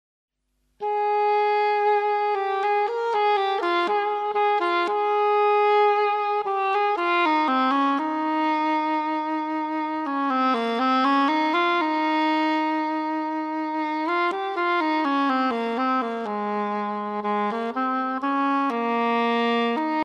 Solo na rożek angielski